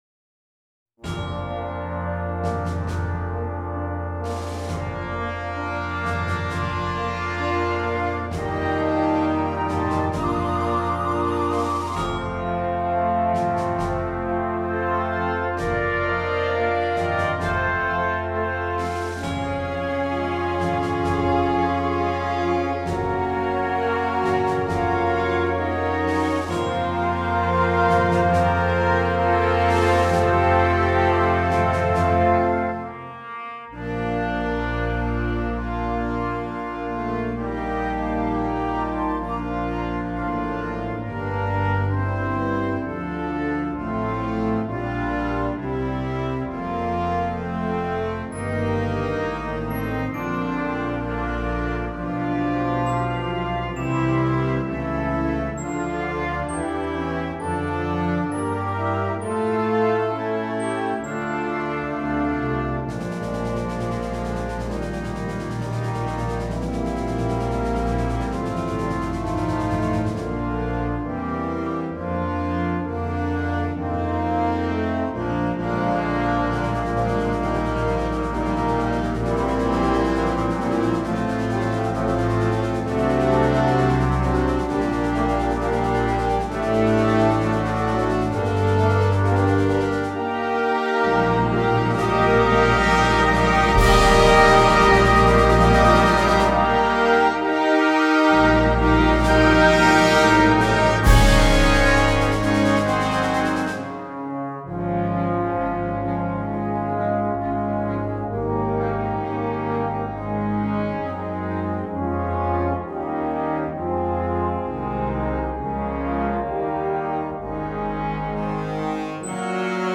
This concert band arrangement
Piccolo
Flutes 1-2
Oboes 1-2
Bb Clarinets 1-2-3
Eb Alto Saxophones 1-2
Horns in F 1-2
Bb Trumpets 1-2-3
Tenor Trombones 1-2
Euphonium
Timpani
Side Drum / Cymbals / Bass Drum
Glockenspiel
Tubular Bells